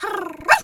pgs/Assets/Audio/Animal_Impersonations/dog_2_small_bark_02.wav at master
dog_2_small_bark_02.wav